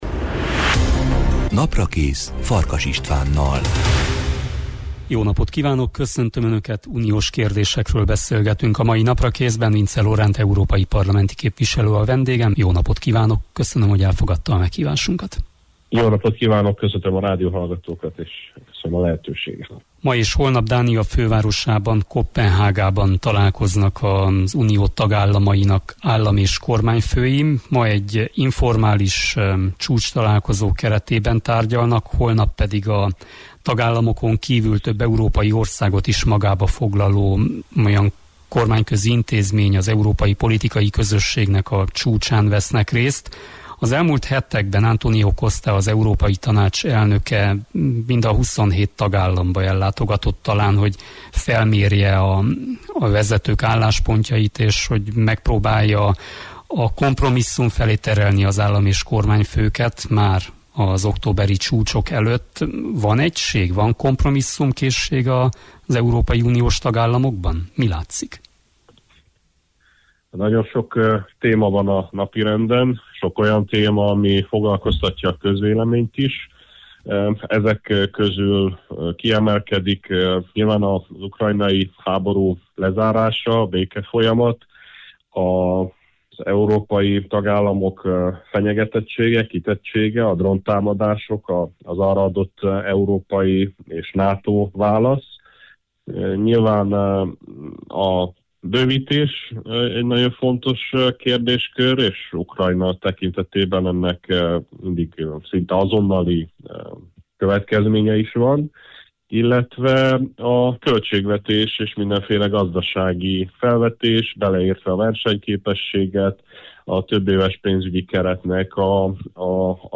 Erről beszélgetünk a Naprakészben, Vincze Loránt európai parlamenti képviselővel.